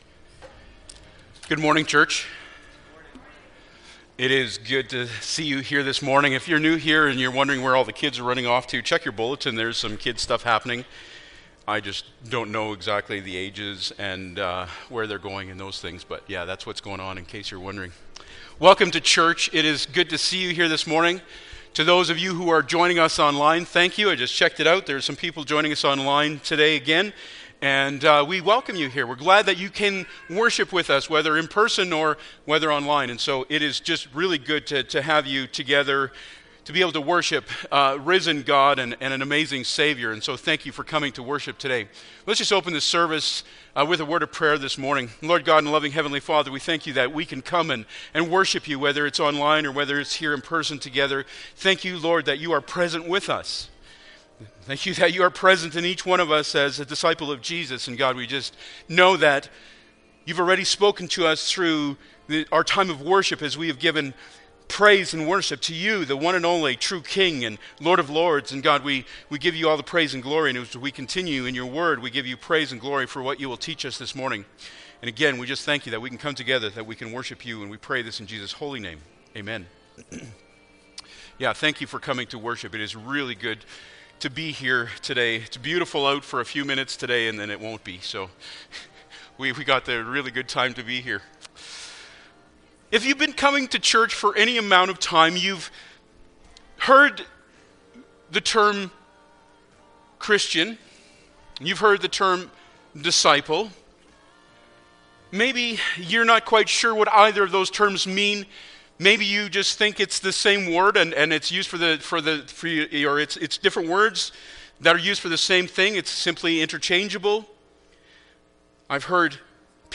Matthew 28:18-20 Service Type: Sunday Morning Bible Text